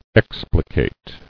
[ex·pli·cate]